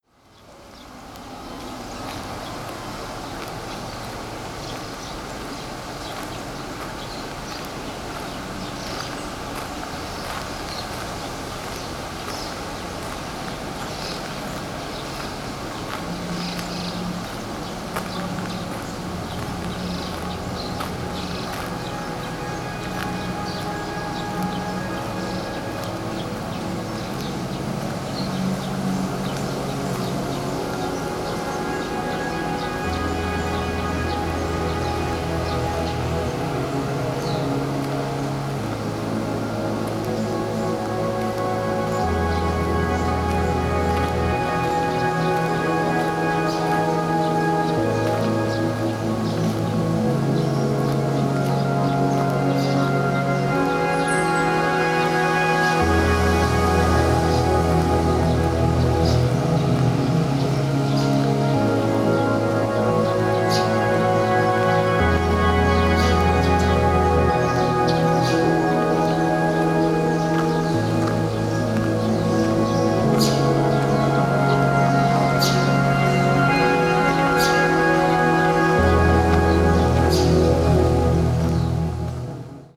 ambient   down tempo   electronic   obscure dance